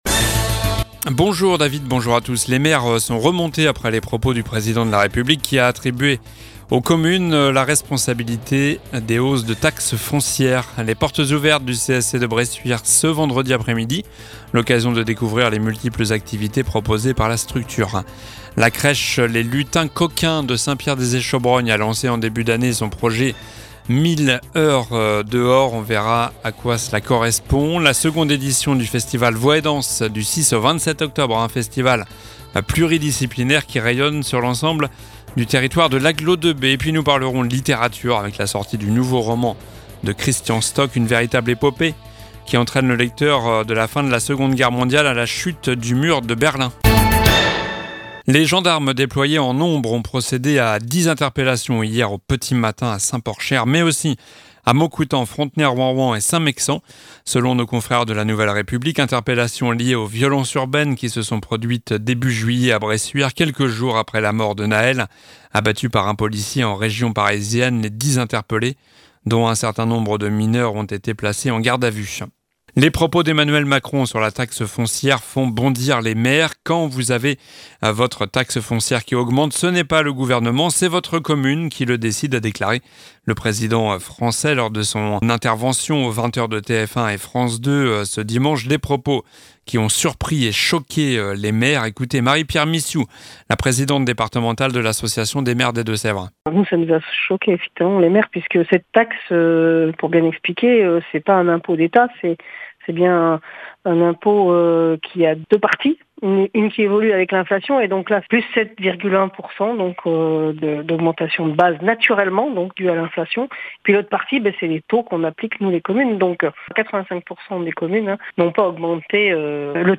Journal du mercredi 27 septembre (midi)